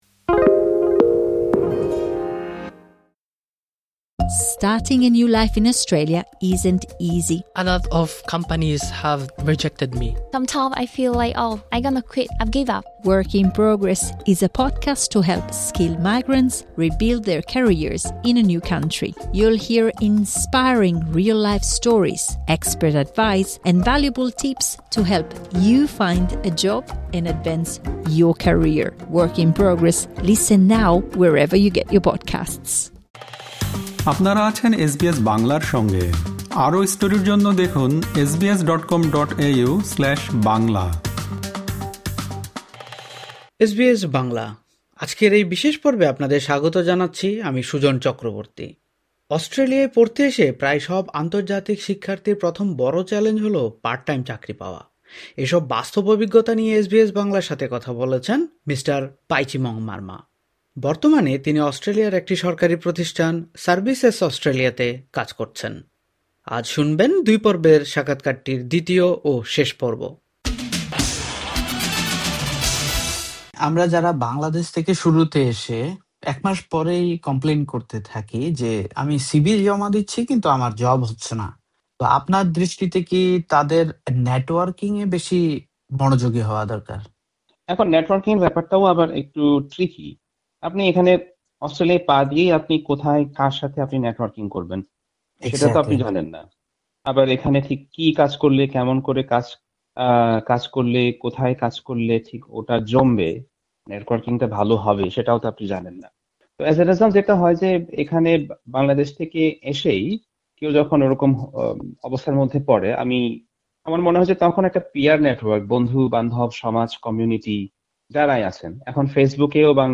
সাক্ষাৎকারের দ্বিতয়ি ও শেষ পর্বে উঠে এসেছে নেটওয়ার্কিংয়ের গুরুত্ব এবং কর্মসংস্থানের সঙ্গে সংশ্লিষ্ট আরও কয়েকটি দিক।